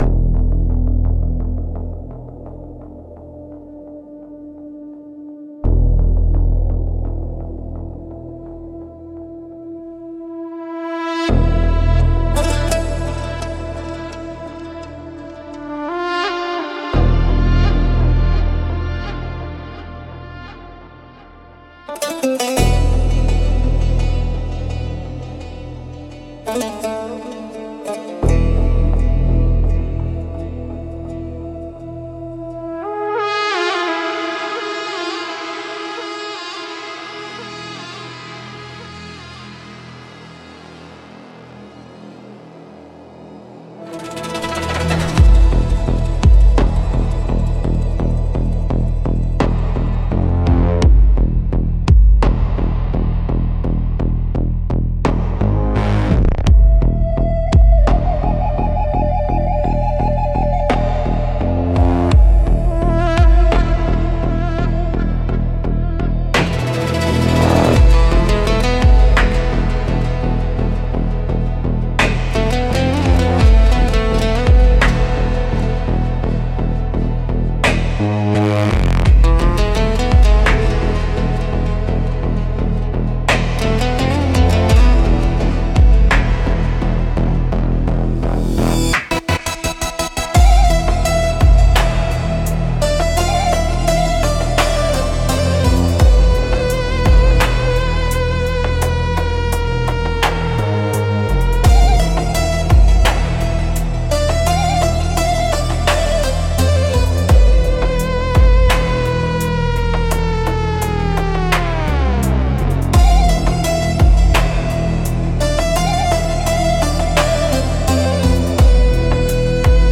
Instrumentals - The Endless Silence of Industry